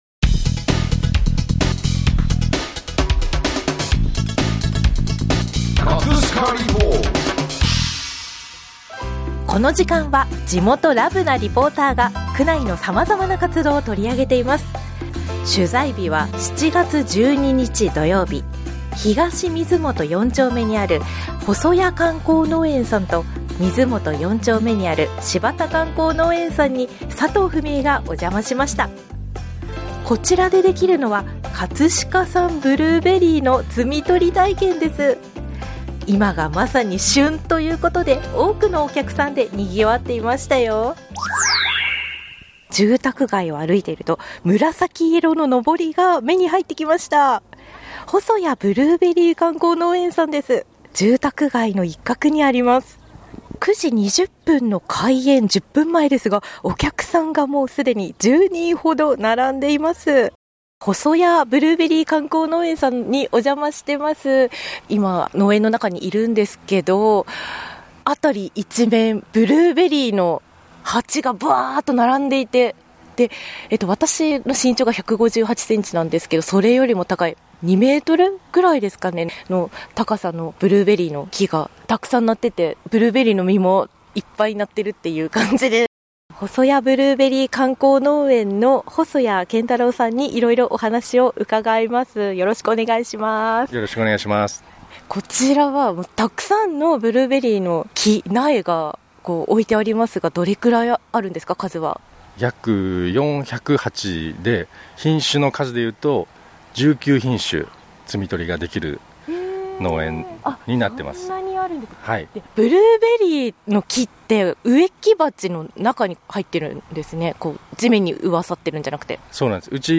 連日猛暑が続いている中で、この日の気温は25度前後でとても過ごしやすい体感♪ 絶好のブルーベリー摘み取り日和だったこともあり、園内は多くのお客さんで賑わっていました！
ブルーベリーの摘み取りを楽しむ皆さんのお声をぜひお聴き下さい♪
▼リポート音声